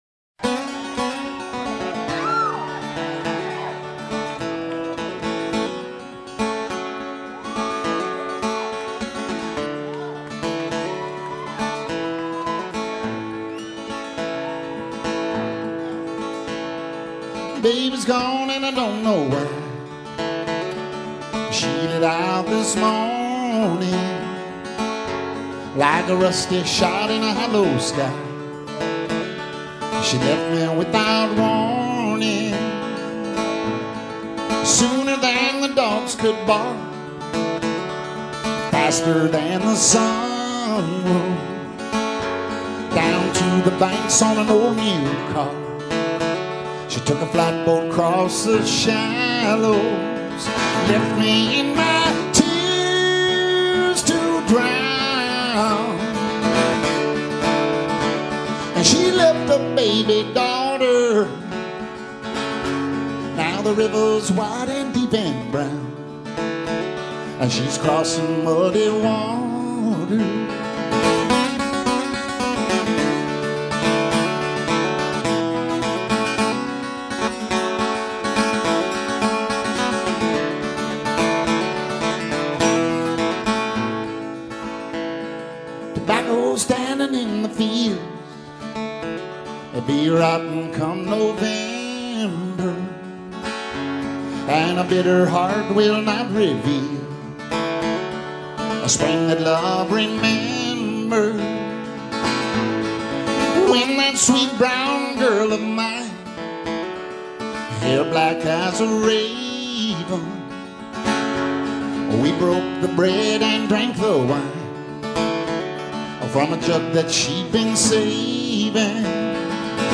Live Bonus Track
recorded live at The Catalyst in Santa Cruz